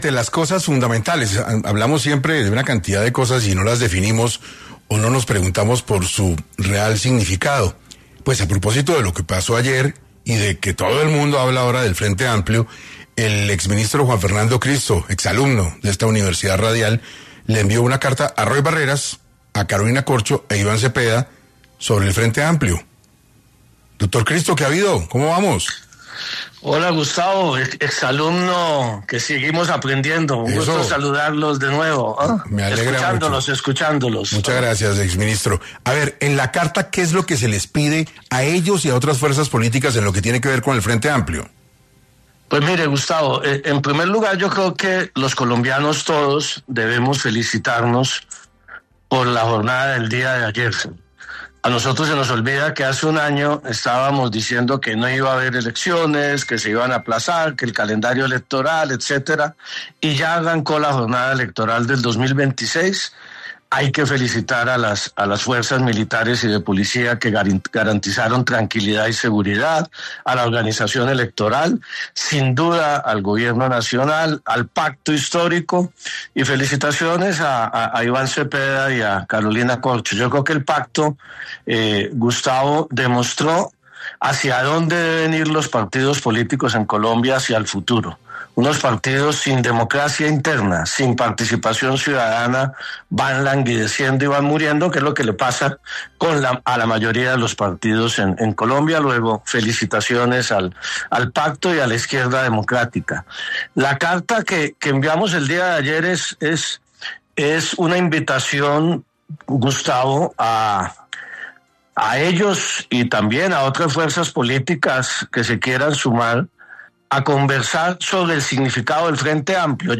Juan Fernando Cristo, exministro del Interior, pasó por 6AM para ampliar la información de la carta que le envió a Iván Cepeda, Carolina Corcho y Roy Barreras, en relación al Frente Amplio.